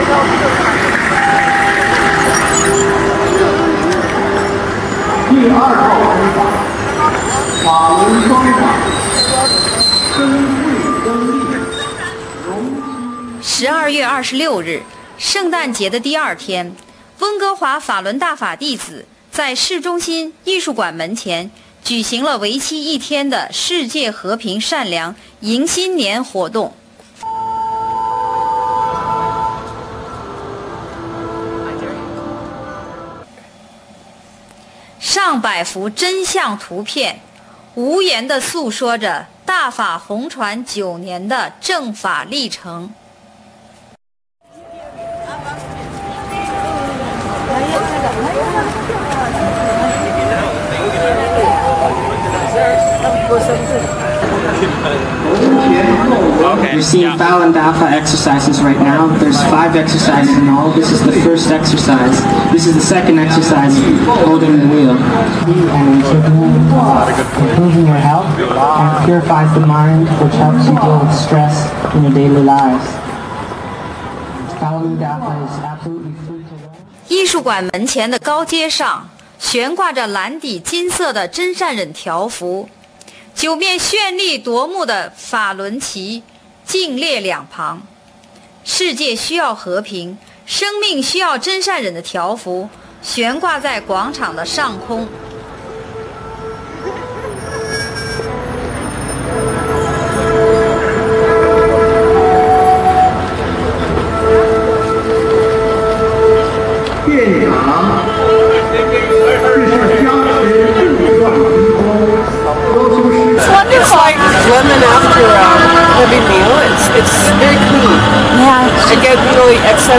Van_news_256k.ra